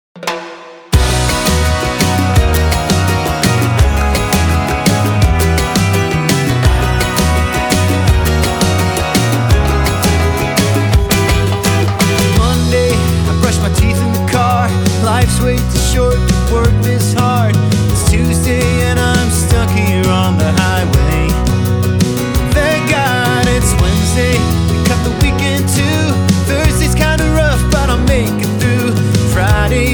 2025-04-25 Жанр: Поп музыка Длительность